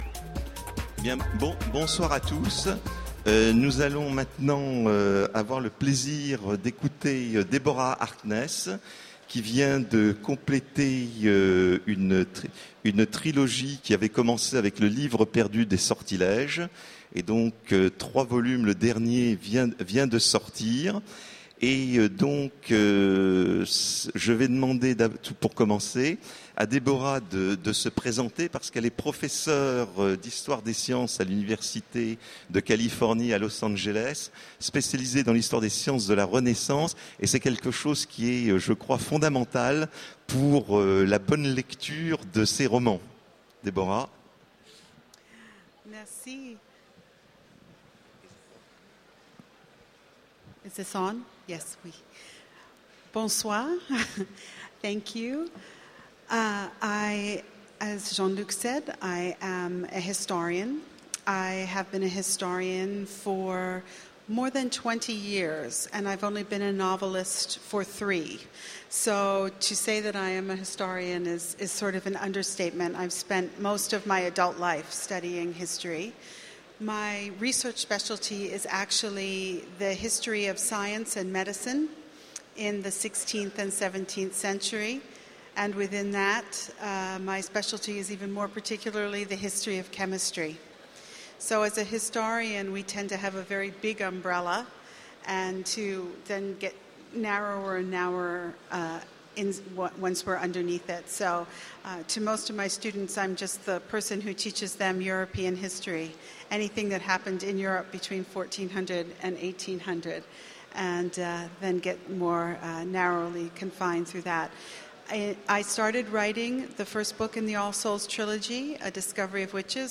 Utopiales 2014 : Rencontre avec Deborah Harkness
- le 31/10/2017 Partager Commenter Utopiales 2014 : Rencontre avec Deborah Harkness Télécharger le MP3 à lire aussi Deborah Harkness Genres / Mots-clés Rencontre avec un auteur Conférence Partager cet article